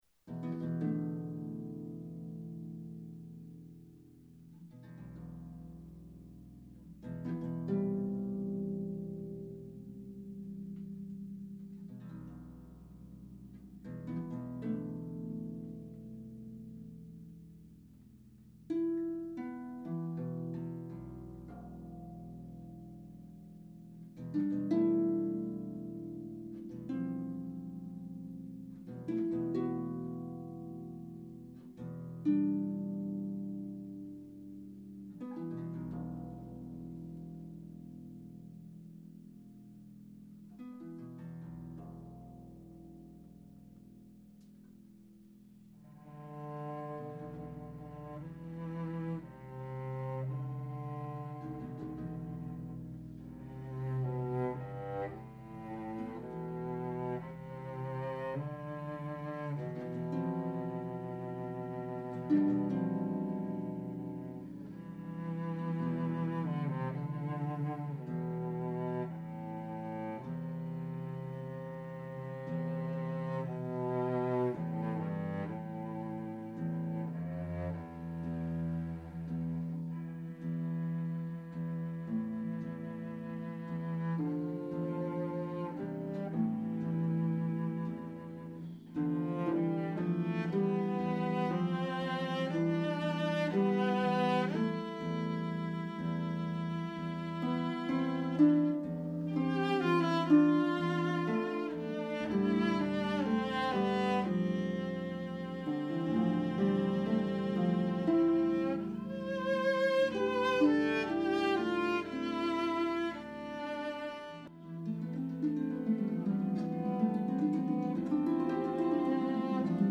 for pedal harp and cello
cello